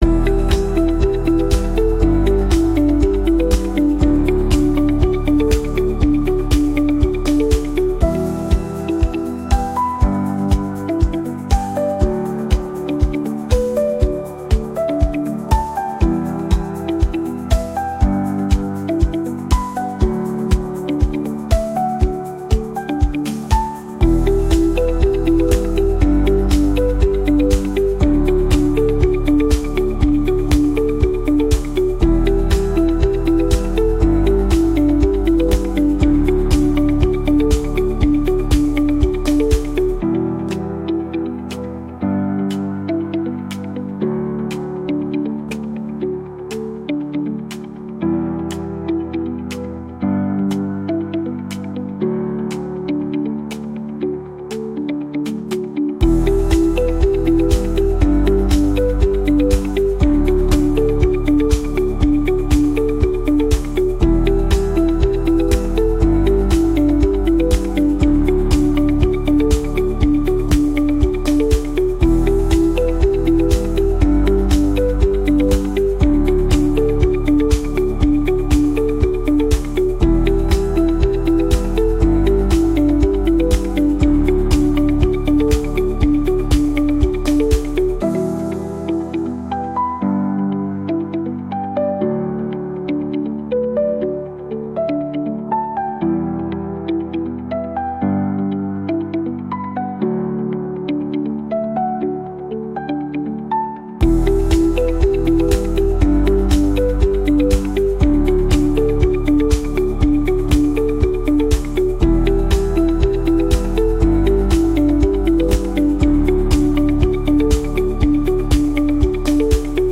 • Качество: Хорошее
• Категория: Детские песни / Музыка детям 🎵